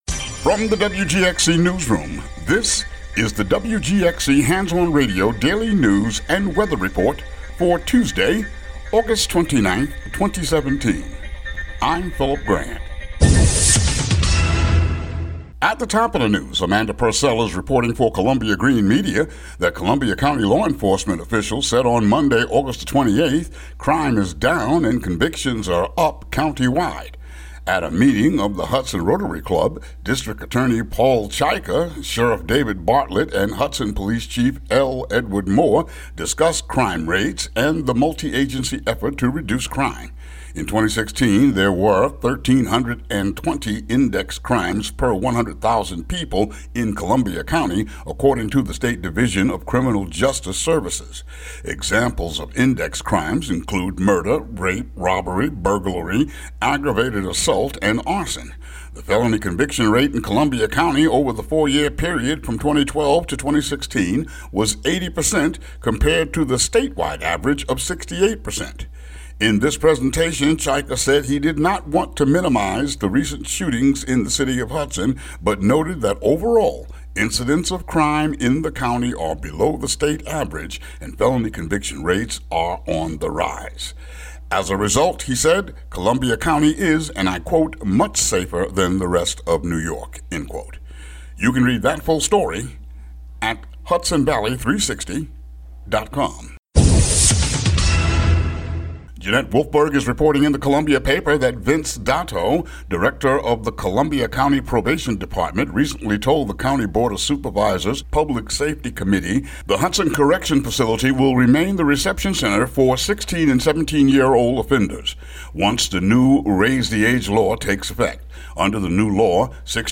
WGXC daily headlines for August 29, 2017.